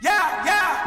TS - CHANT (8).wav